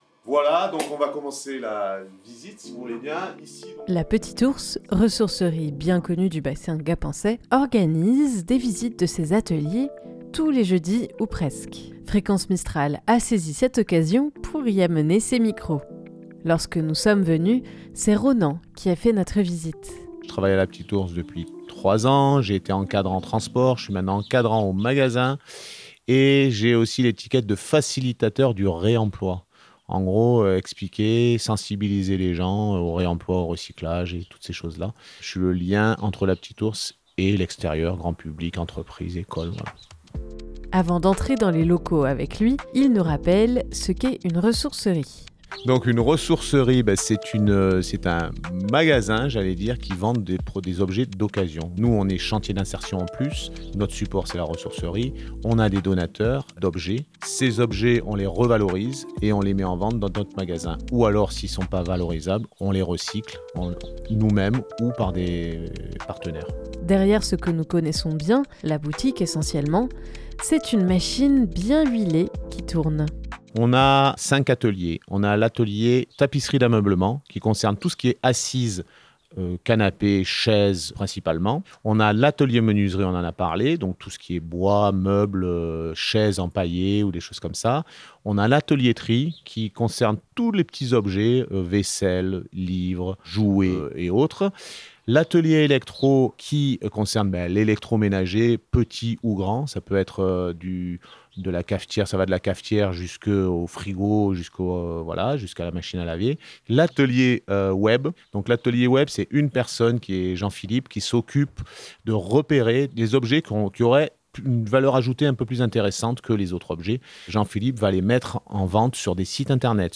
Fréquence Mistral a saisi cette occasion pour y amener ses micros.